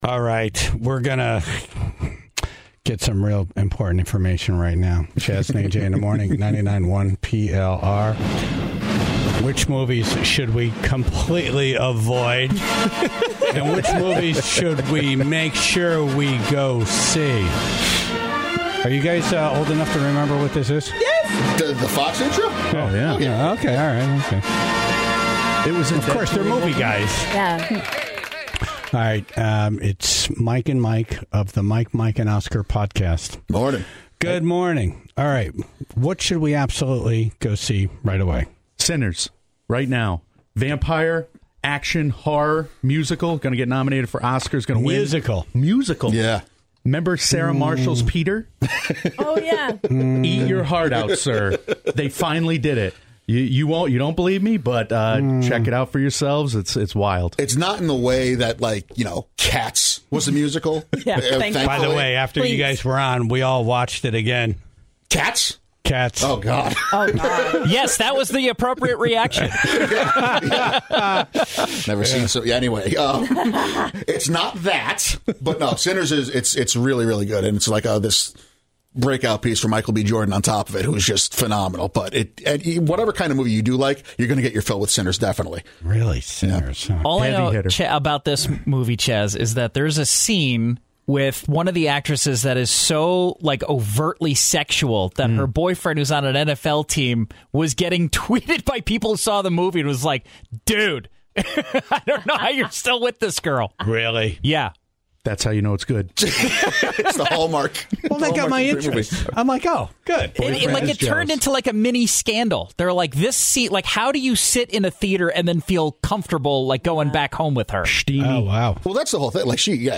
were in studio